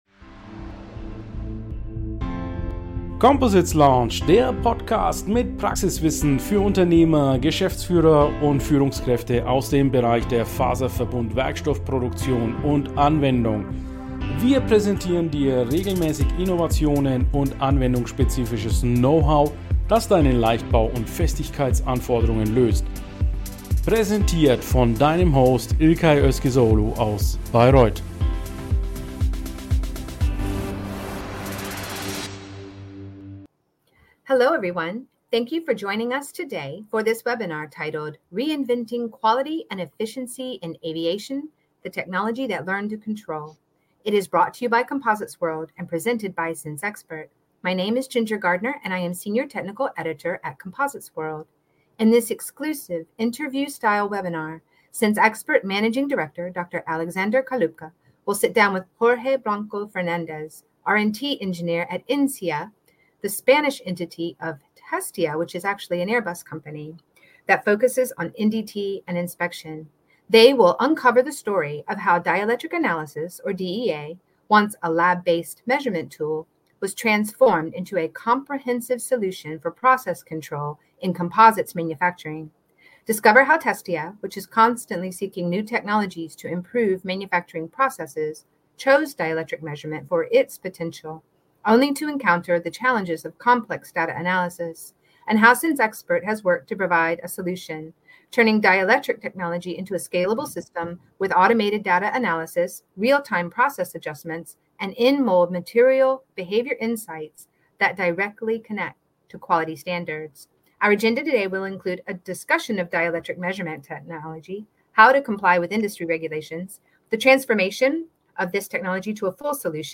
interview-style webinar